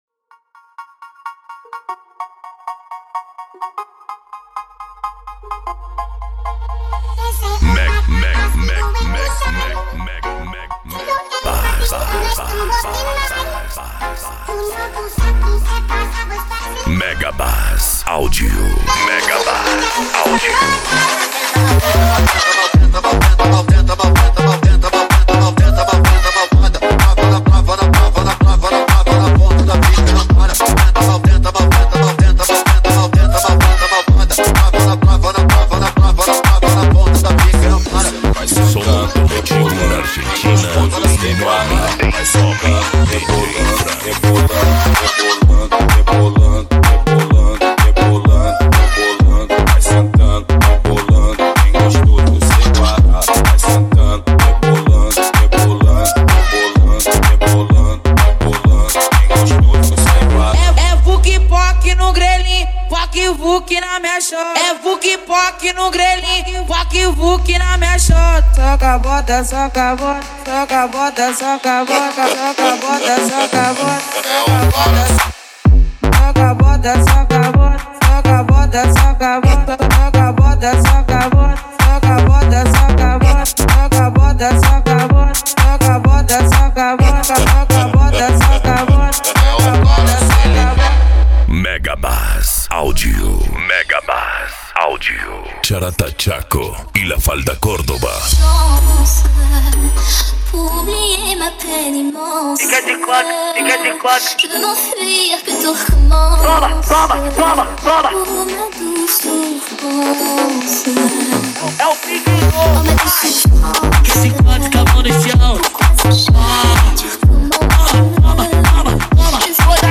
Bass
Funk
Mega Funk
Minimal